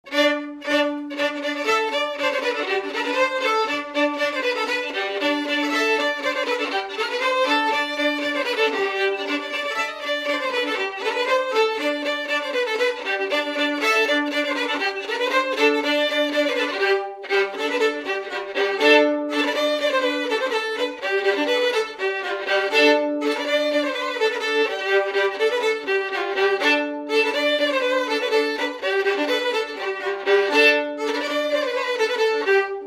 Mémoires et Patrimoines vivants - RaddO est une base de données d'archives iconographiques et sonores.
Résumé instrumental
danse : branle : avant-deux
Catégorie Pièce musicale inédite